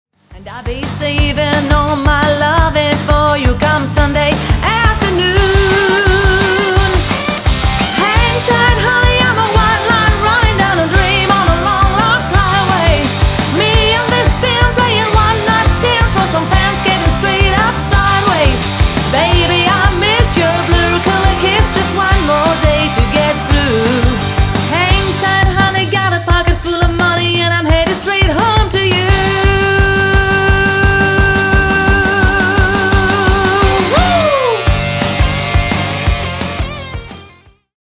Tour de chant 100% country.